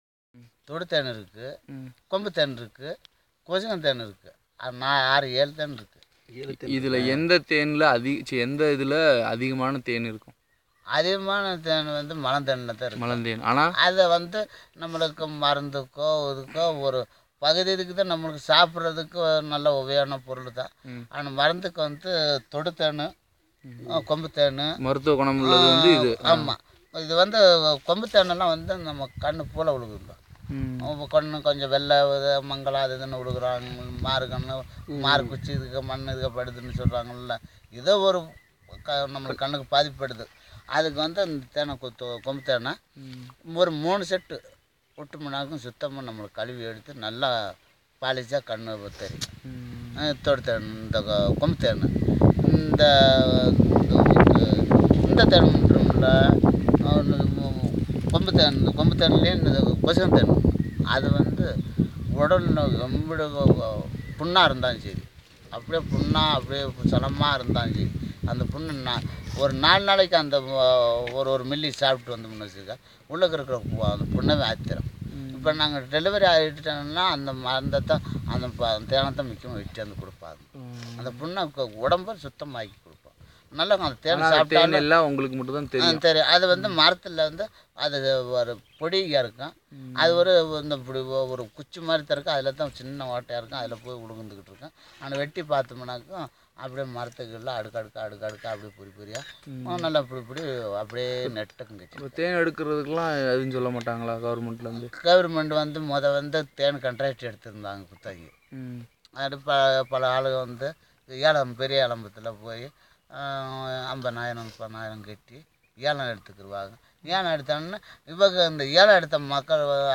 Conversation about gathering different types of honey and its medicinal properties
NotesThis is a conversation between the consultant and the principal investigator about the gathering of honey and different types of honey.